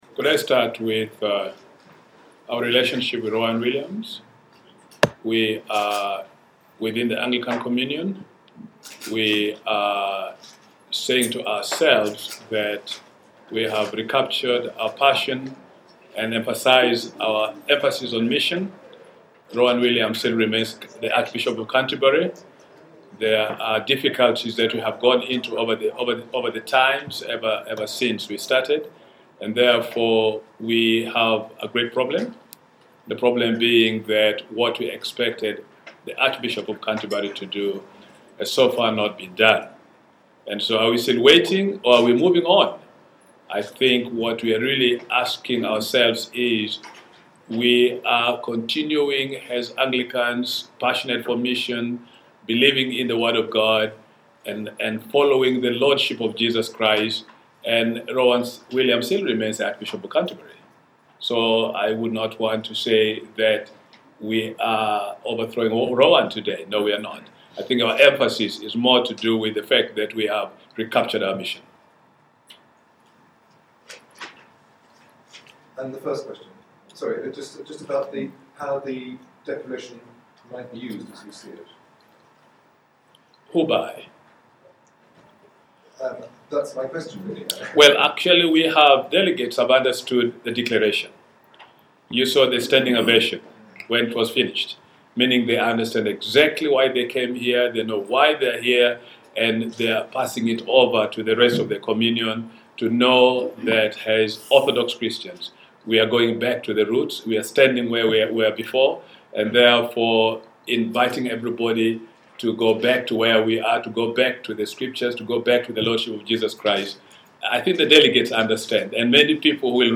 Also hear a 6 minute recording from Sunday afternoon’s press conference (Archbishops Henry Orombi, Peter Jensen and Emmanuel Kolini answer questions) –
finalbriefing.mp3